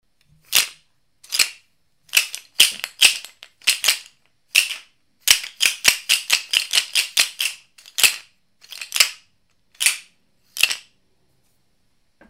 Decorated Sistrum Rattle with gourd disks, from Kenya.
Branch-system-gourd.mp3